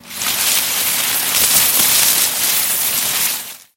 Шорох кустов когда мимо прошел человек